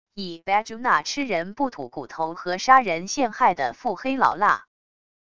以badguy那吃人不吐骨头和杀人陷害的腹黑老辣wav音频